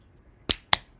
clap-02_volume0.wav